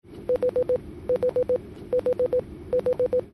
Blind Spot Information System (BLIS) chime (
Chime_BLIS.mp3